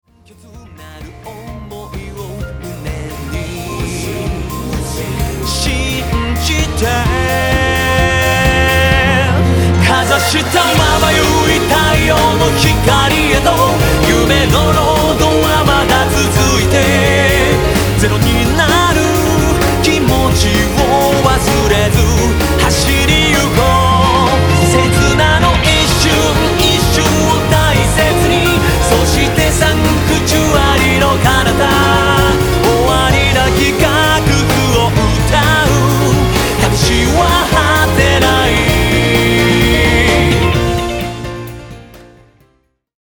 アイドルソング